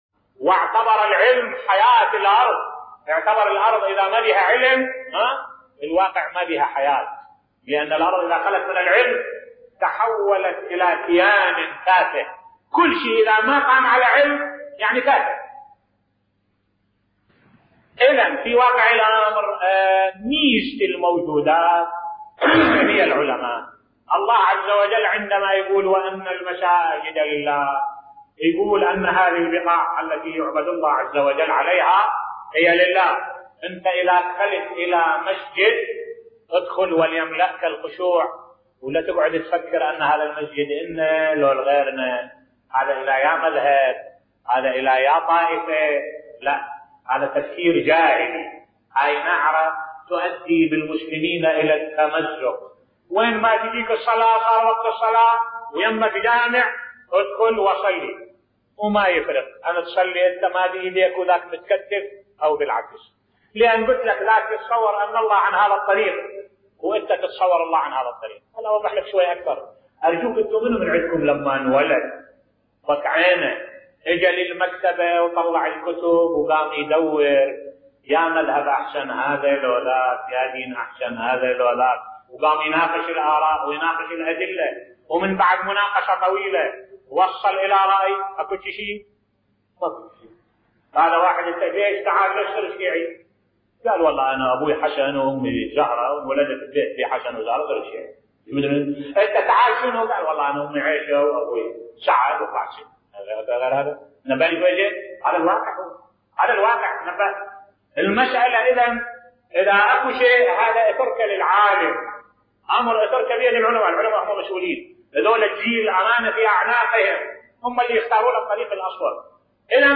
ملف صوتی صلوا في كل في مسجد ولا تكترثوا لمذهب من يرتاد المسجد بصوت الشيخ الدكتور أحمد الوائلي